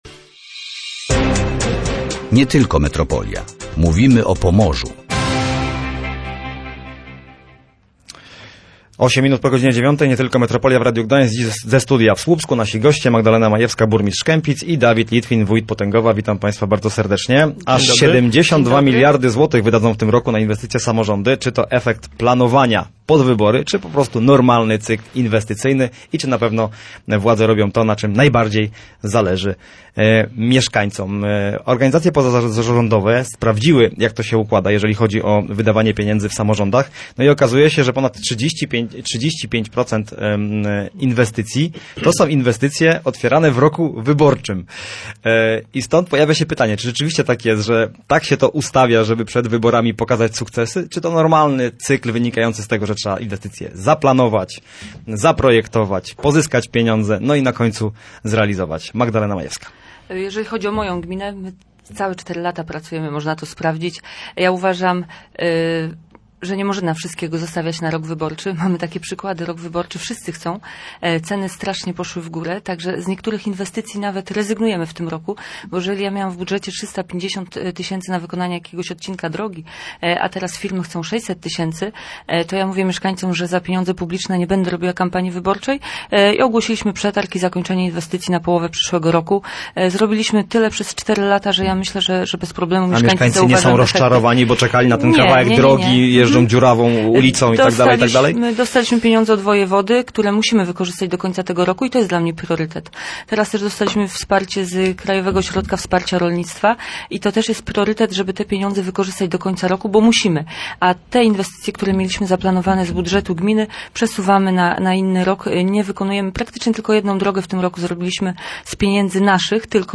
Magdalena Majewska – burmistrz Kępic i Dawid Litwin – wójt Potęgowa.